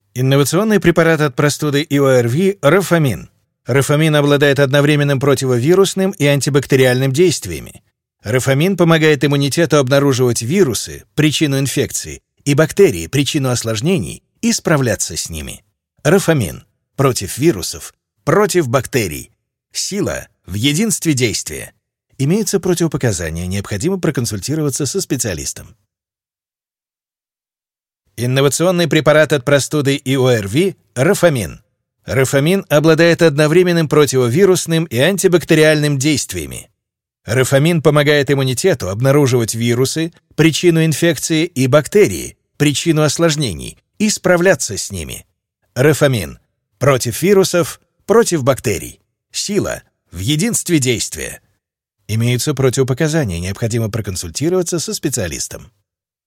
Voice-Over Talent (Recording studio).
Микрофон: CharterOak